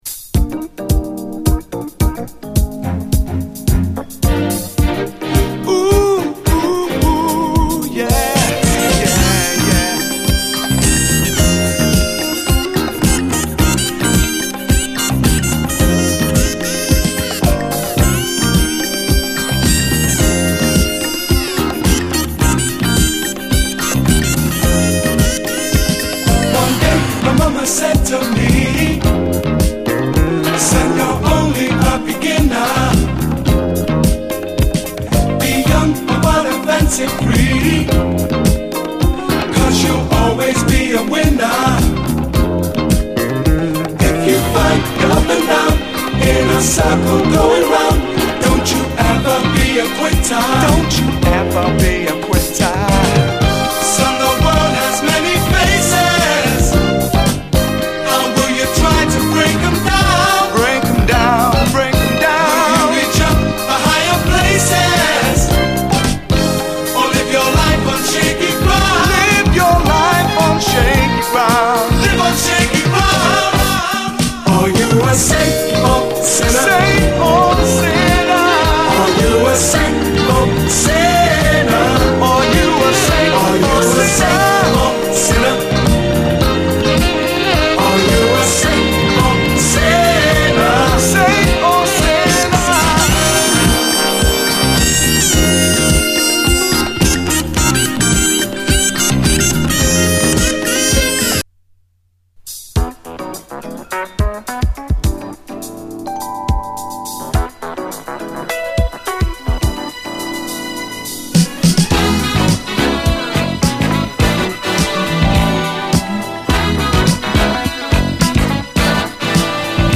SOUL, 70's～ SOUL, DISCO
内容最高のUK産モダン・ソウル盤！